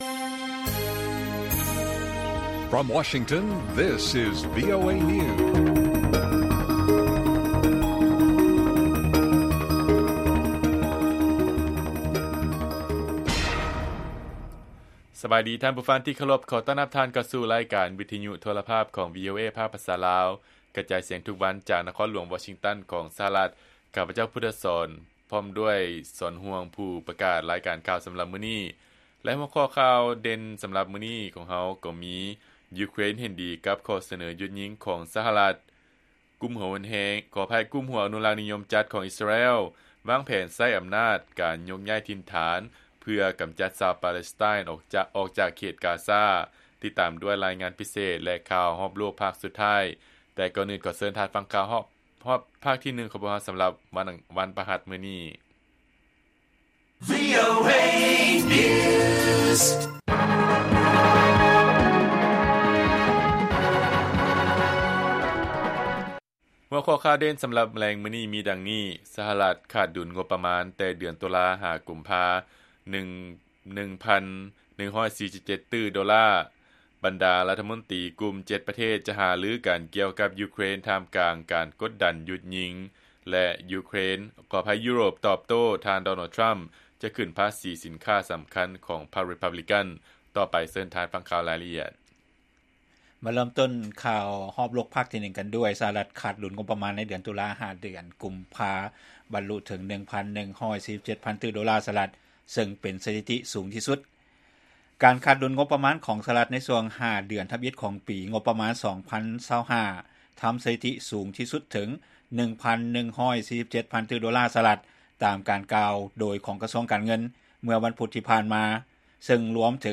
ລາຍການກະຈາຍສຽງຂອງວີໂອເອ ລາວ: ສະຫະລັດ ຂາດດຸນງົບປະມານແຕ່ເດືອນ ຕຸລາຫາກຸມພາ 1147 ຕື້ໂດລາ